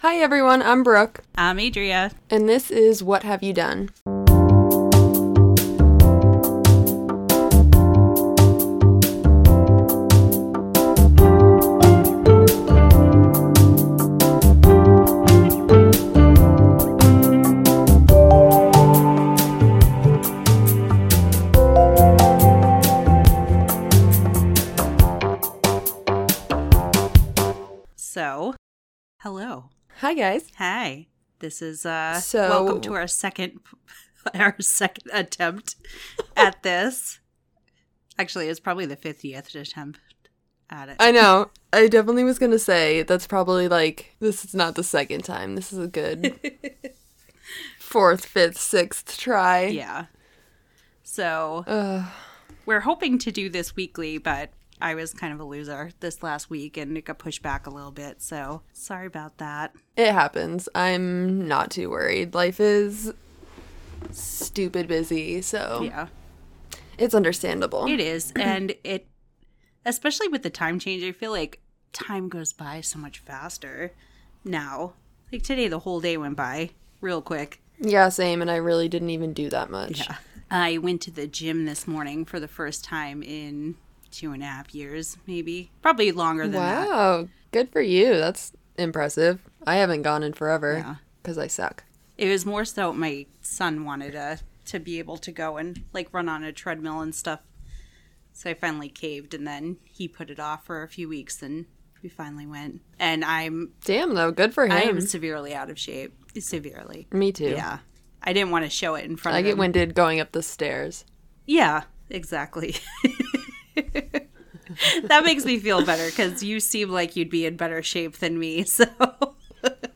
Come listen to some creepy knocking and learn about my creepy apartment